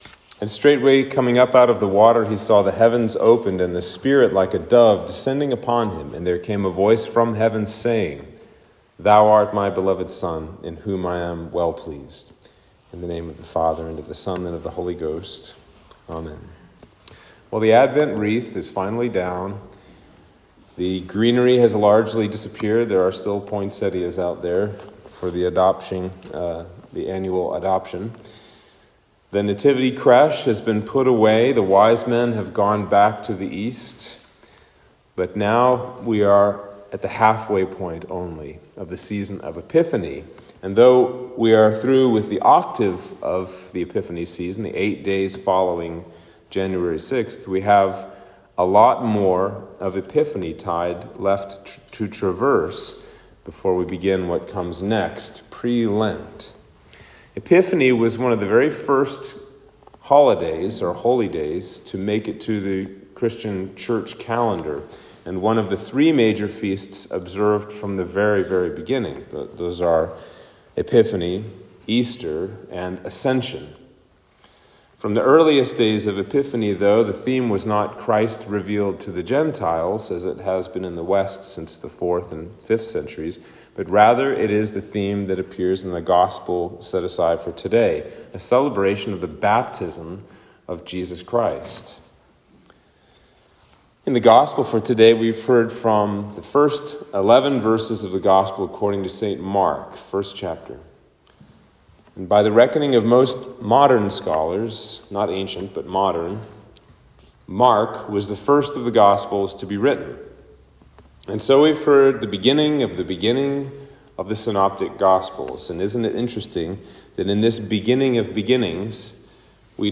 Sermon for Epiphany 2